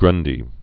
(grŭndē)